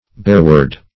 Search Result for " bearward" : The Collaborative International Dictionary of English v.0.48: Bearward \Bear"ward`\, n. [Bear + ward a keeper.]
bearward.mp3